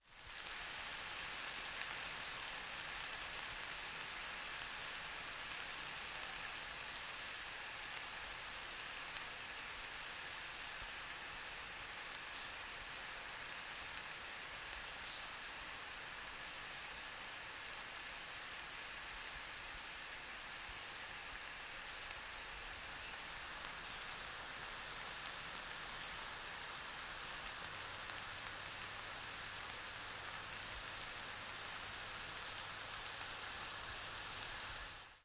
Звук Грибов - Послушайте ещё раз